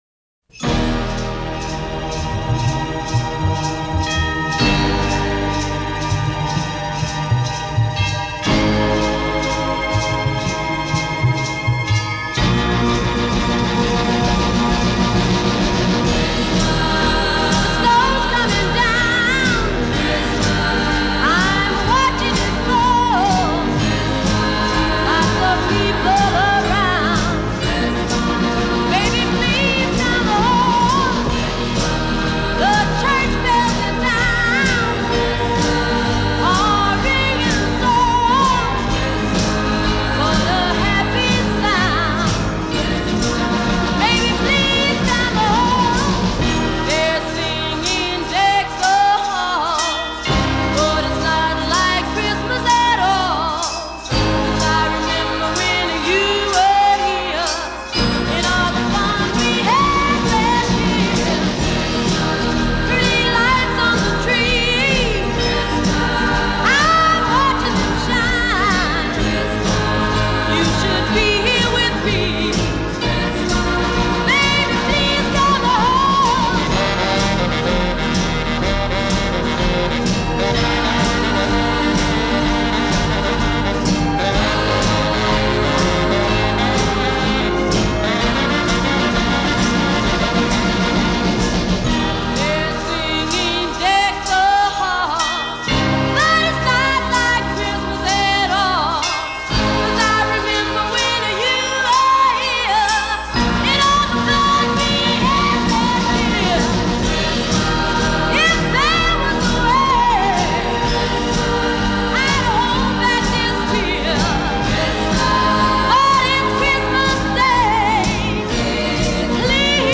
This song is a pocket opera.